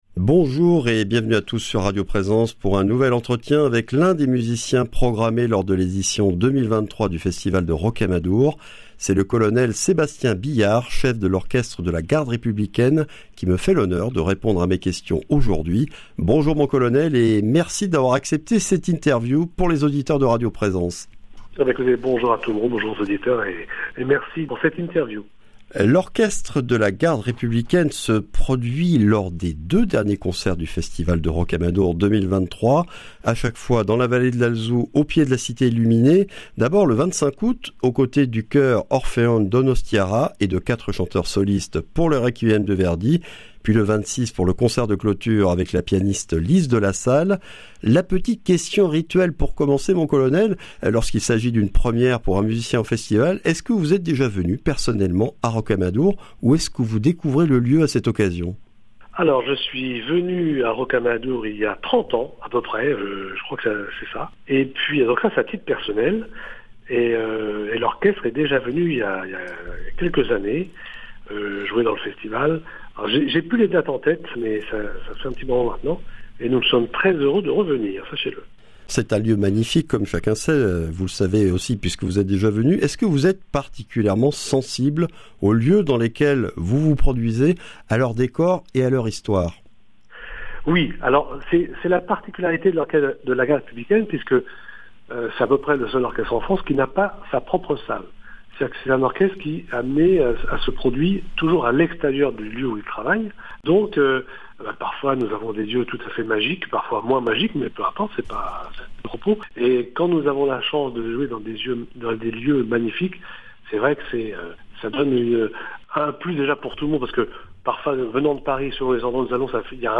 Entretien avec le chef d’un des orchestres français les plus prestigieux et populaires.